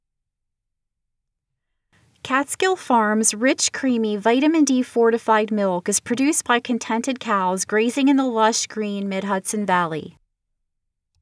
Both of your last two tests (982.73 KiB) & (967.73 KiB) sound very boxy to me.